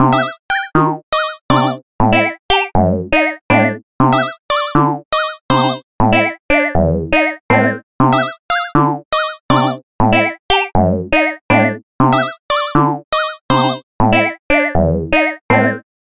Sounds Like An Early 8-bit Nintendo Melody. Very Silly!